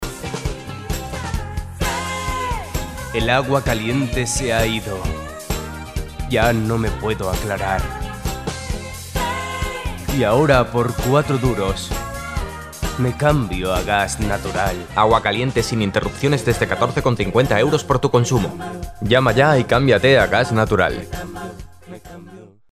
Voz joven, cálida y con brillo. Entonación especial, modulada, y con gran variedad de registros
kastilisch
Sprechprobe: Werbung (Muttersprache):